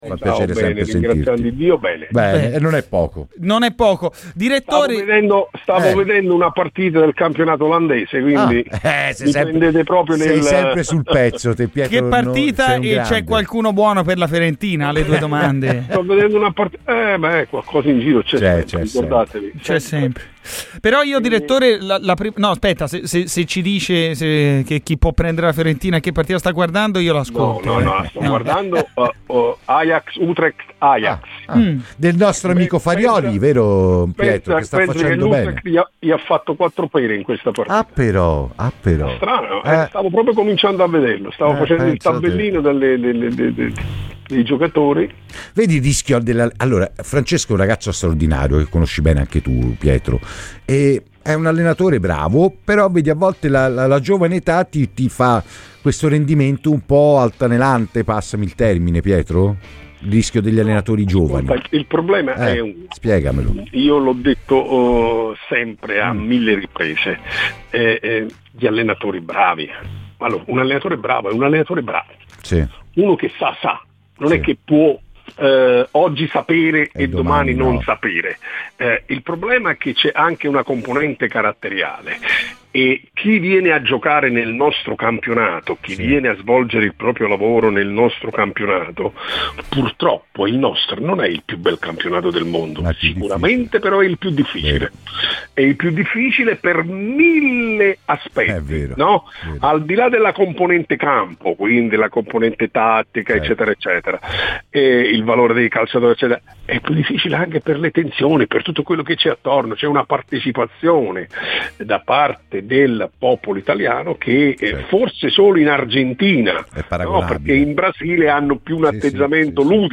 Ascolta l'intervista completa con tutte le considerazioni del dirigente dal podcast